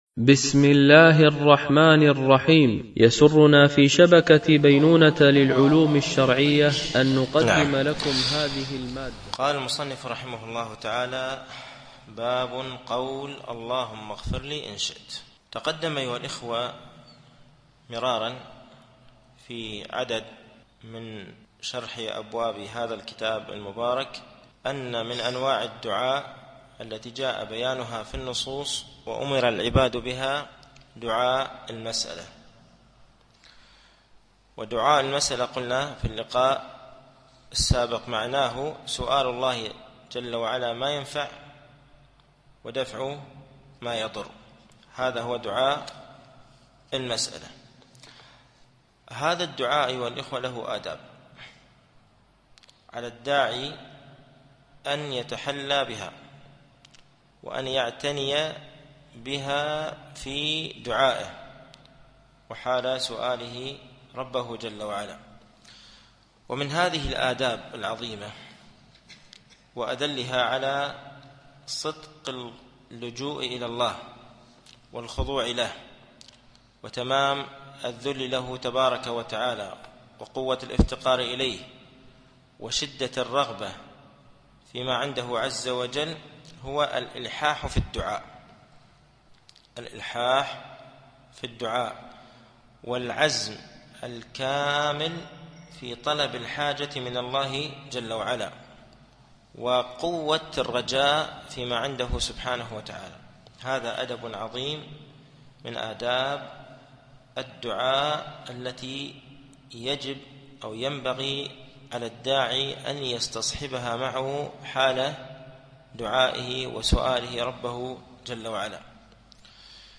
التعليق على القول المفيد على كتاب التوحيد ـ الدرس الرابع و الأربعون بعد المئة